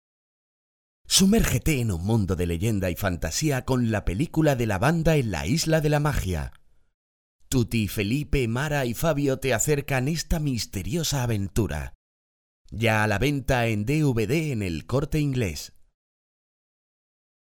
Medium age male, warm, dynamic, confident and deep or young male, funny and very clear
kastilisch
Sprechprobe: eLearning (Muttersprache):